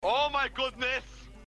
oh-my-godness_d7N68OY.mp3